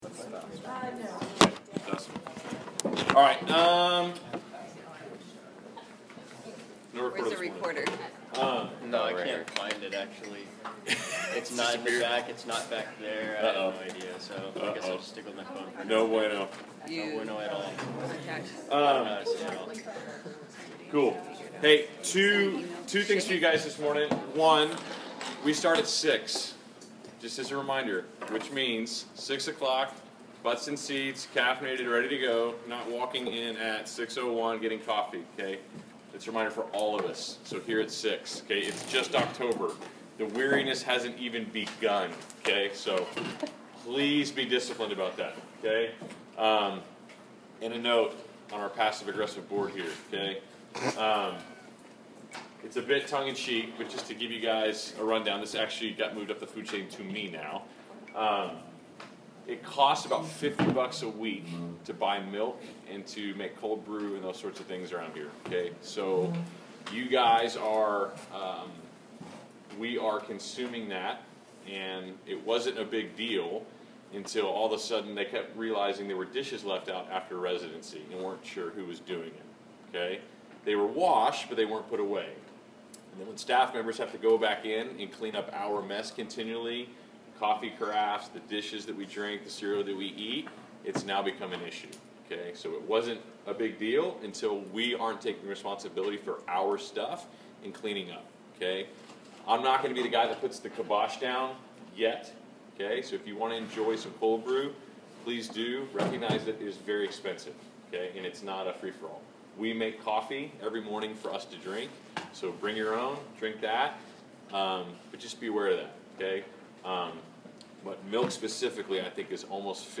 Class Session Audio October 13